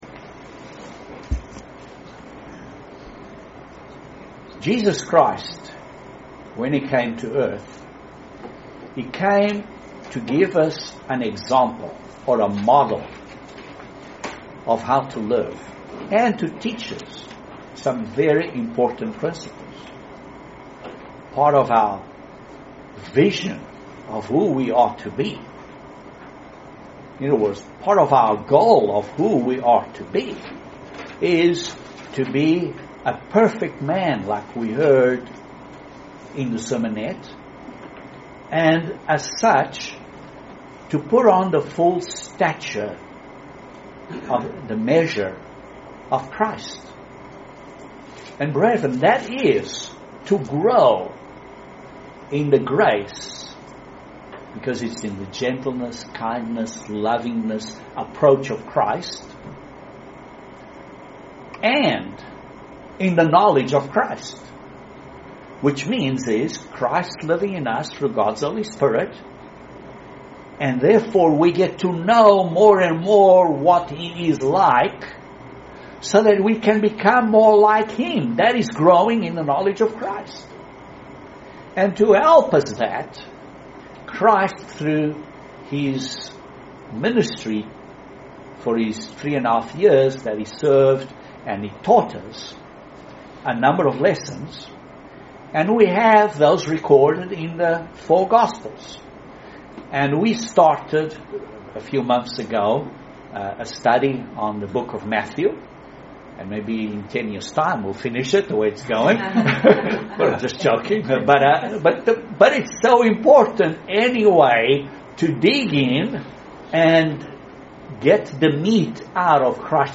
Bible Study Matthew 5:6-12